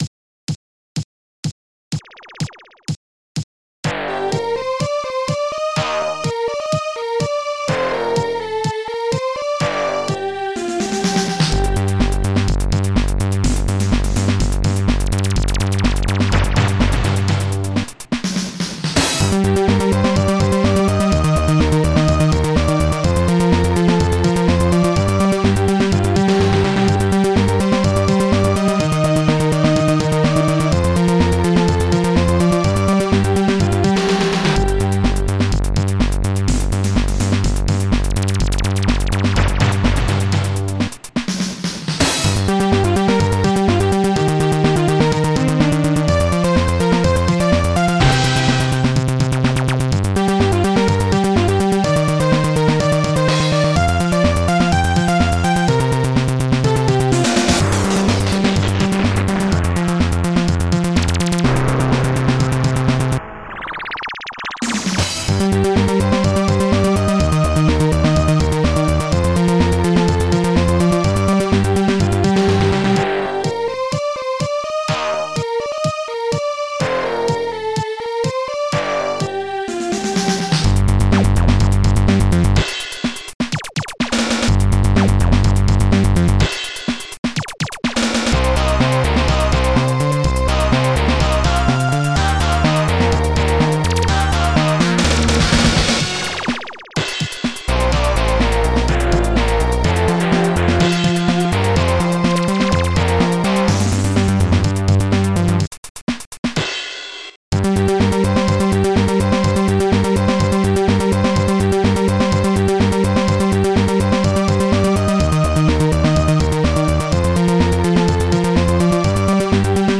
Protracker and family
ST-01:SYNBRASS
ST-01:STRINGS6
ST-01:POLYSYNTH
ST-99:CRASH